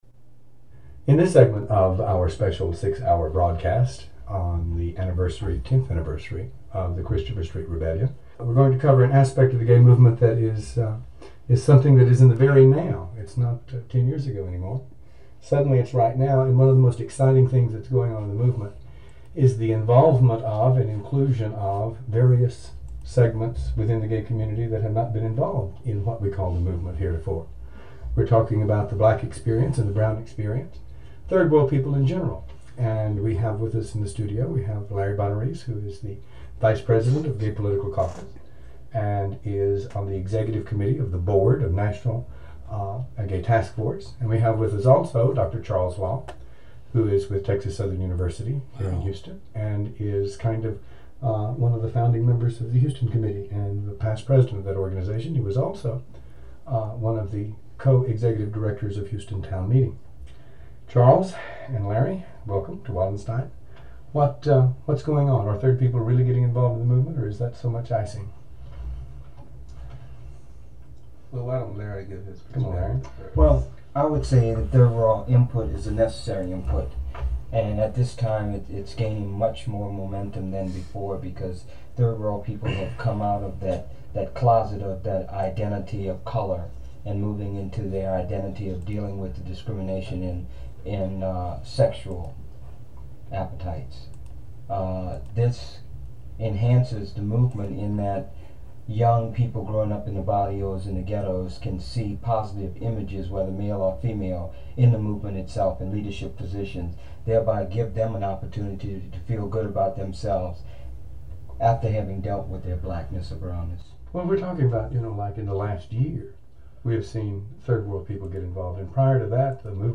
newly discovered interview!